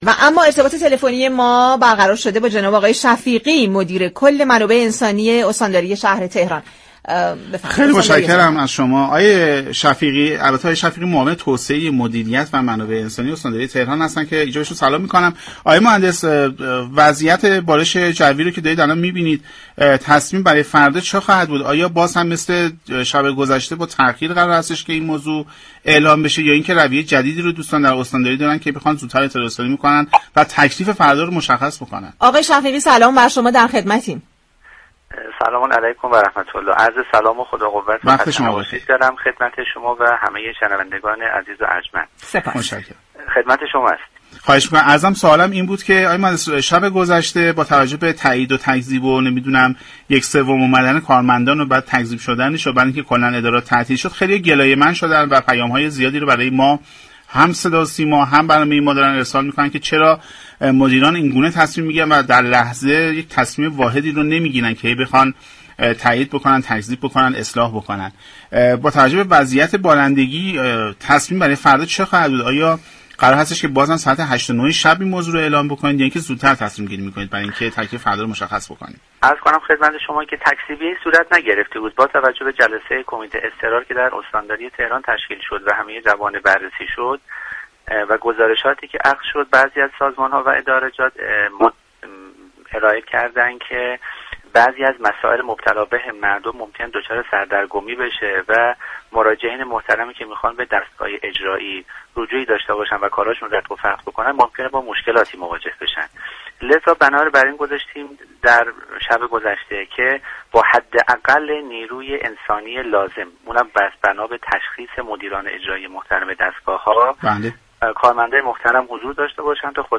دریافت فایل به گزارش پایگاه اطلاع رسانی رادیو تهران، برنامه سعادت آباد 25 دی ماه در گفتگو با قدرت الله شفیقی معاون توسعه مدیریت و منابع انسانی استانداری تهران در پاسخ به اینكه با توجه به بارش های امروز؛ تصمیم برای فردا دوشنبه 26 دی چیست؟